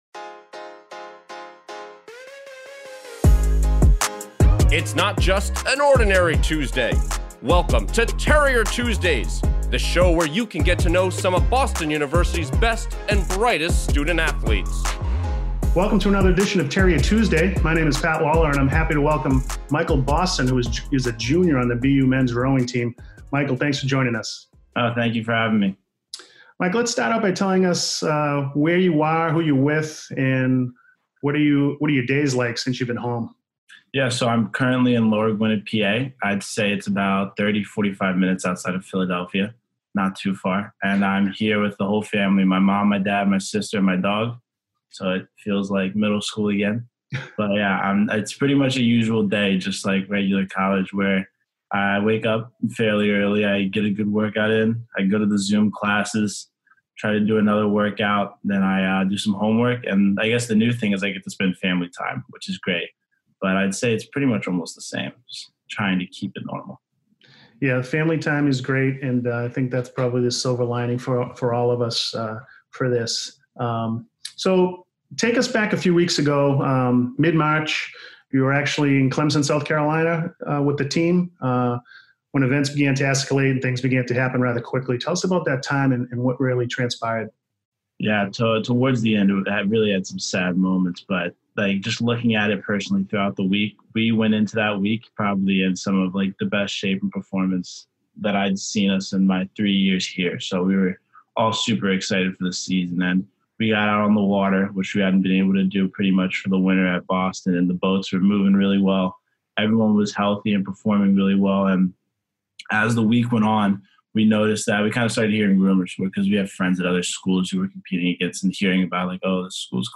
Each week we'll feature a new interview with one of our student-athletes.